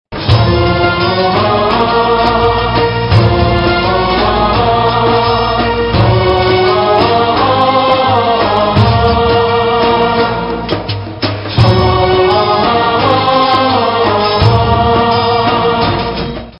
زنگ خور موبایل